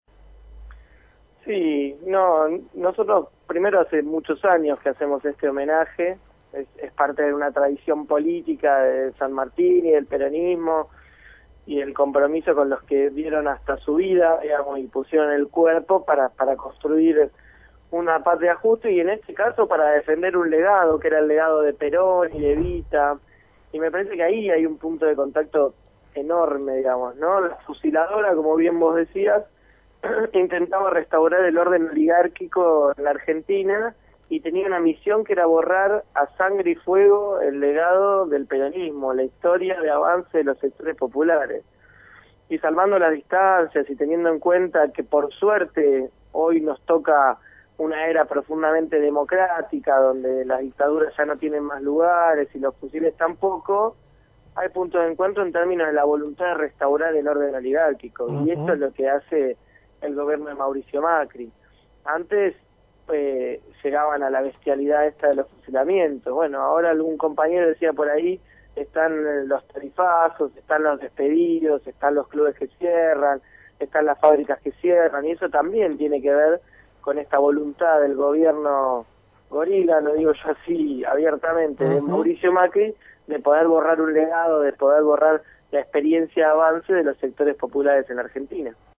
leonardoGrosso(09/06/2016) Leonardo Grosso, Diputado Nacional del Movimiento Evita, conversó con Caídos del Catre sobre el homenaje organizado por el Frente Para la Victoria en el partido de San Martín a sesenta años de los fusilamientos de José León Suárez.